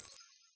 Ice1.ogg